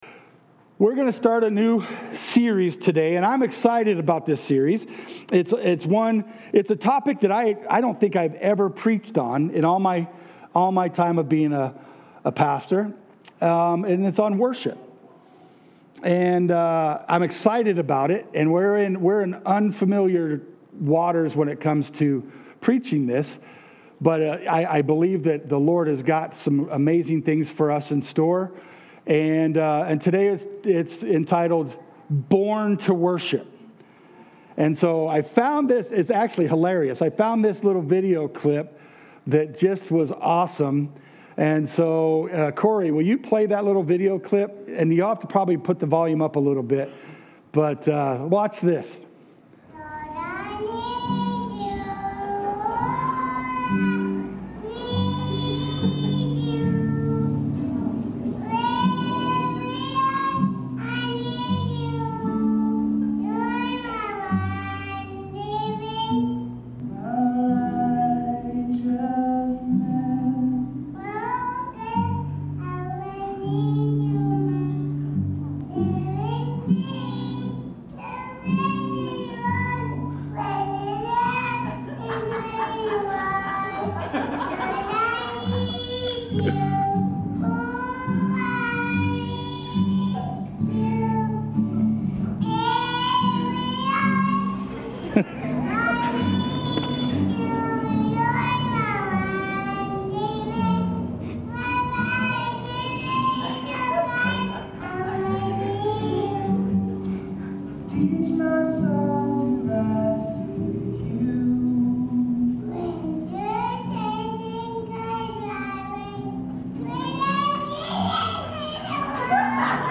Sermons | New Life Fellowship Conrad